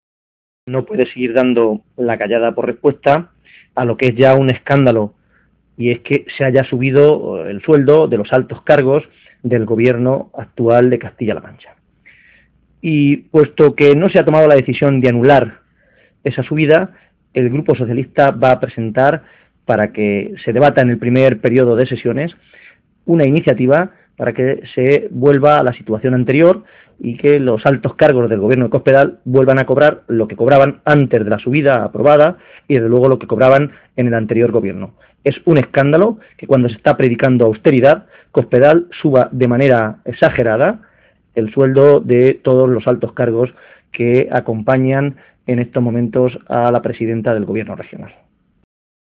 José Manuel Caballero, secretario de organización del PSOE de Castilla-La Mancha
Cortes de audio de la rueda de prensa